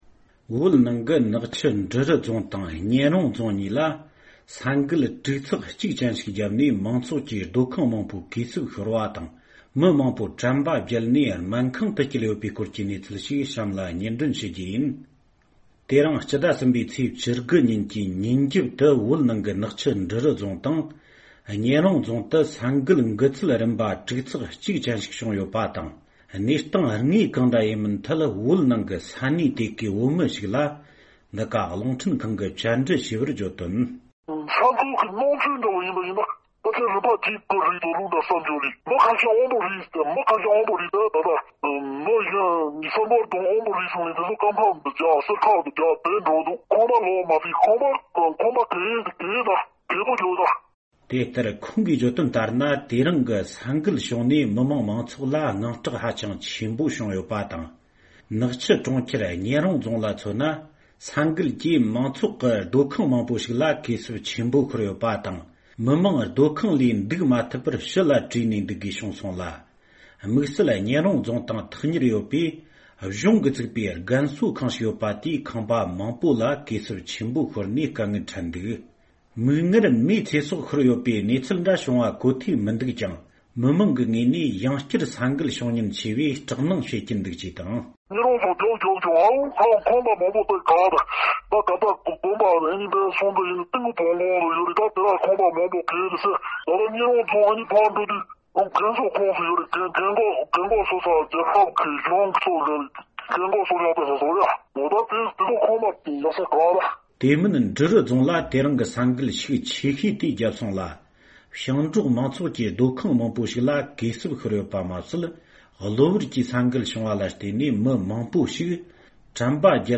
༄༅།། དེ་རིང་སྤྱི་ཟླ་༣་པའི་ཚེས་༡༩་ཉིན་གྱི་ཕྱི་དྲོར་བོད་རང་སྐྱོང་ལྗོངས་ཀྱི་ནག་ཆུ་ས་ཁུལ། འབྲི་རུ་རྫོང་དང་གཉན་རོང་རྫོང་གཉིས་ས་ཡོམ་འགུལ་ཚད་༦་དང་གྲངས་ཆུང་༡་ཅན་ཞིག་བརྒྱུགས་ཡོད་ཅིང་། བོད་ནང་གི་ས་གནས་དེ་གའི་བོད་པ་ཞིག་ལ་འདི་ག་རླུང་འཕྲིན་ཁང་གི་གསར་འགོད་པས་བཅར་འདྲི་ཞུས་པ་ལྟར་ན།